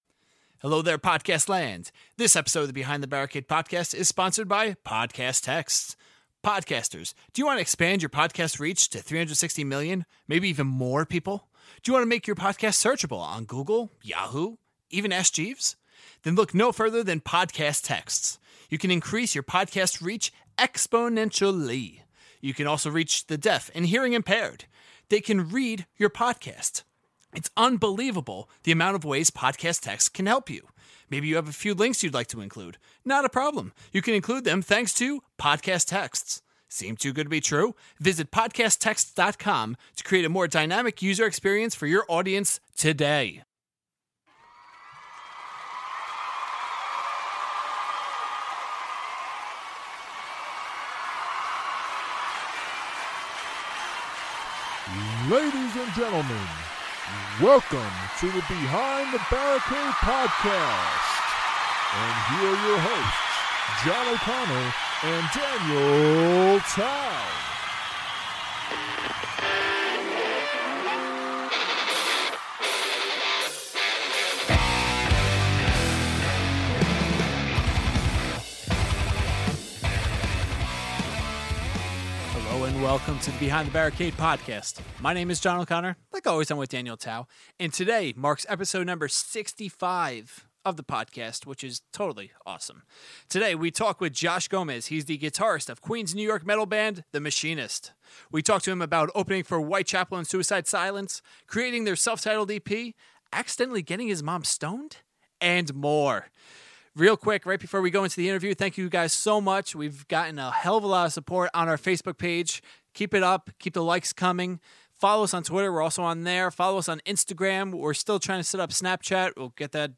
Pre-Interview Song: Wake Up Post-Interview Song: Sudden Death